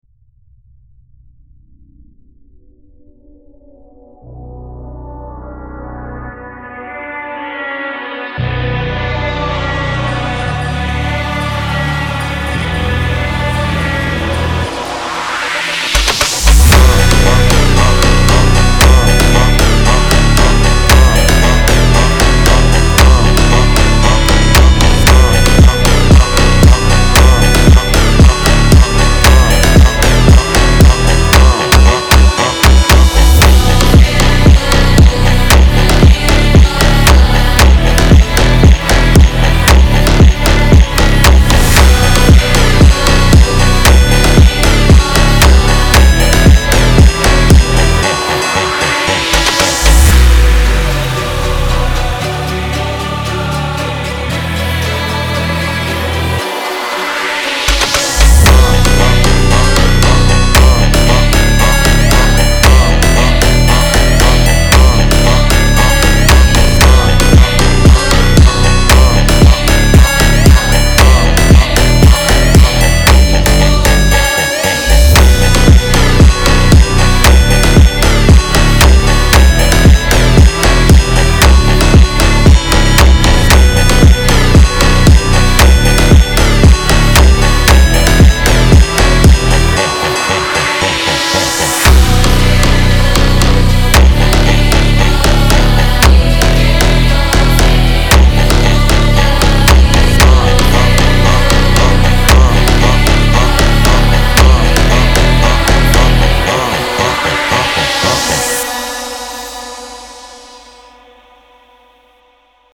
Трек размещён в разделе Зарубежная музыка / Фонк.